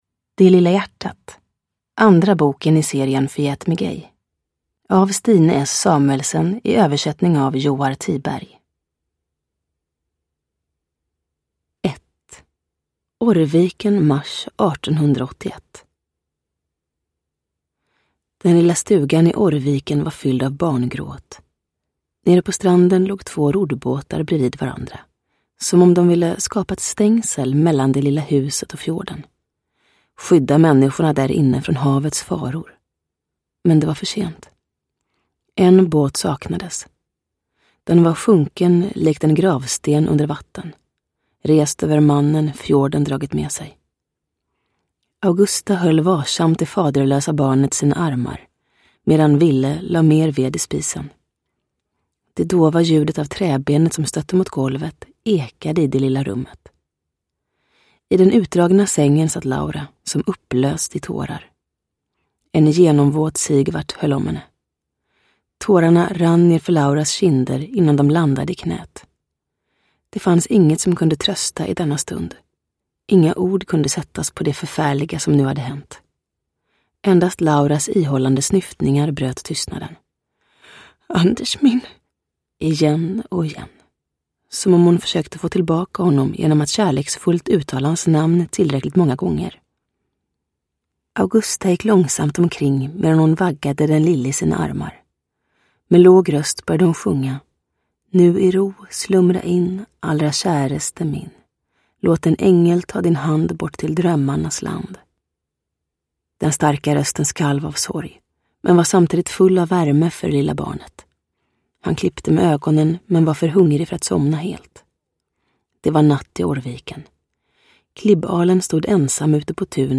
Det lilla hjärtat – Ljudbok – Laddas ner
Uppläsare: Julia Dufvenius